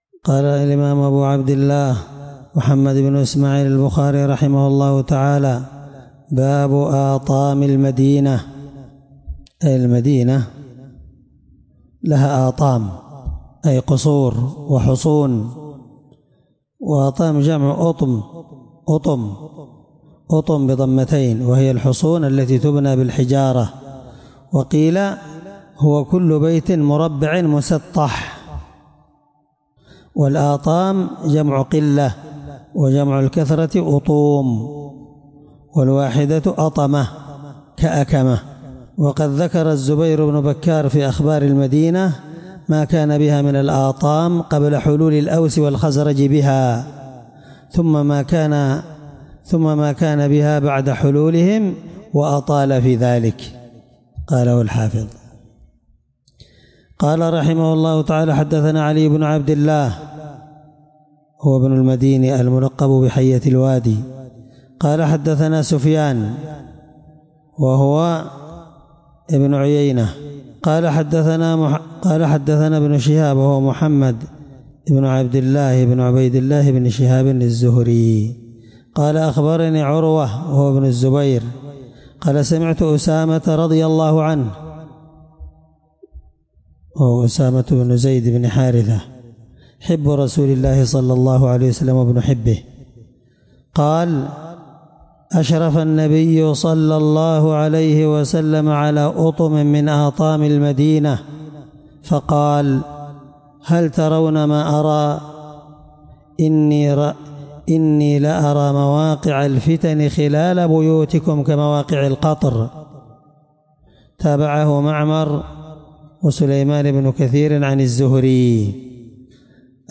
الدرس9من شرح كتاب فضائل المدينة حديث رقم(1878 )من صحيح البخاري